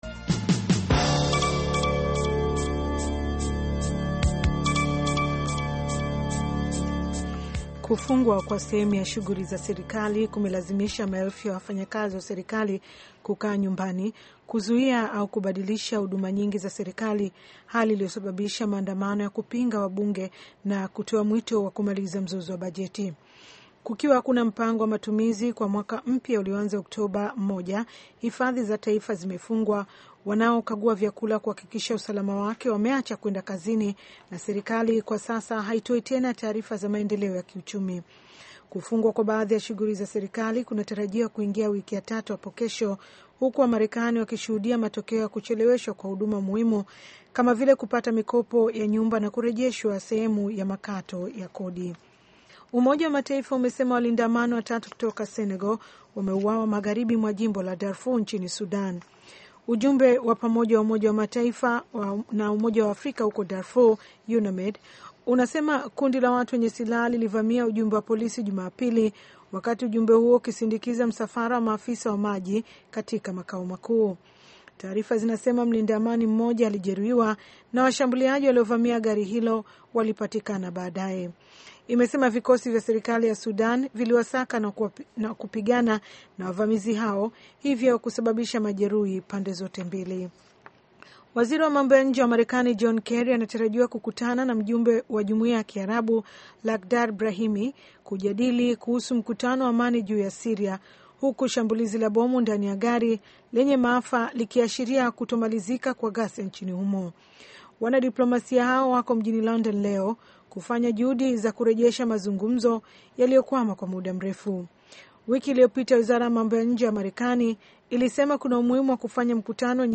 Taarifa ya Habari VOA Swahili - 6:05